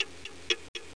Clock.mp3